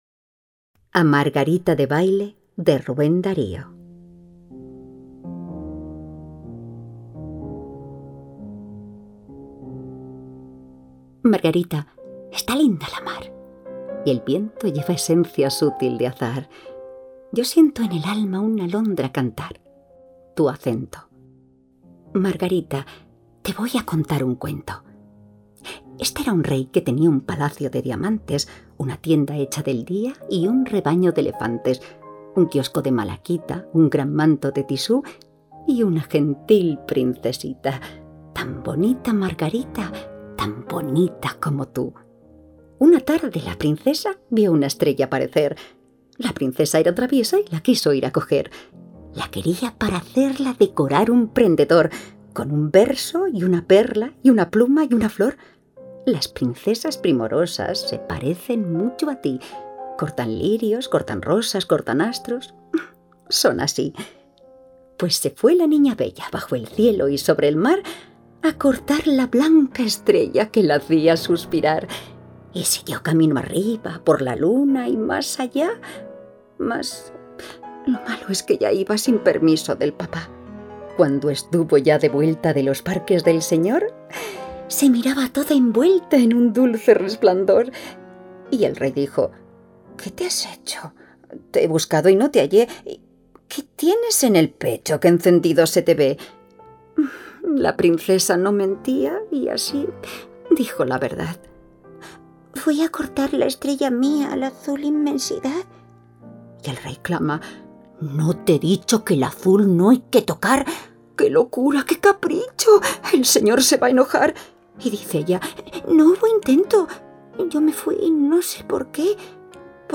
A Margarita Debayle : Audiolibro
Poema-Cuento
Música: MusOpen (cc:by-nc-sa)